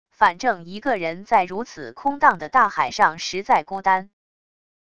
反正一个人在如此空荡的大海上实在孤单wav音频生成系统WAV Audio Player